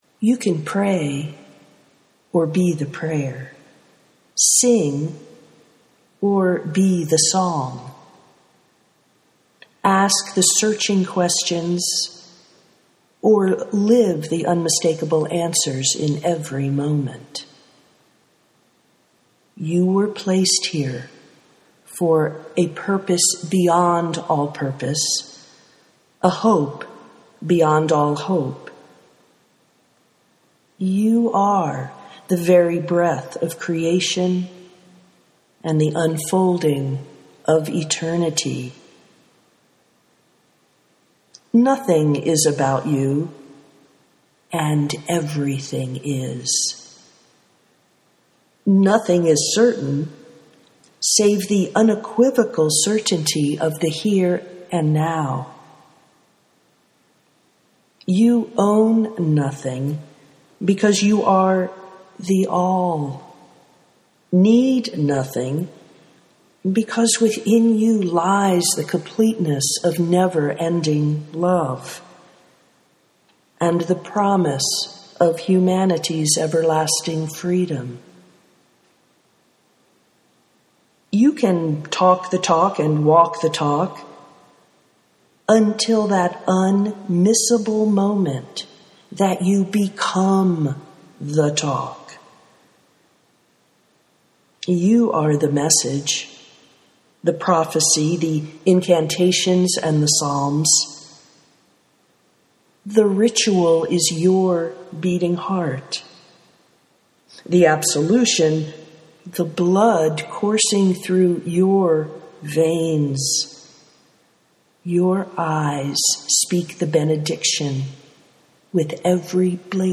you are the prayer (audio poetry 2:35)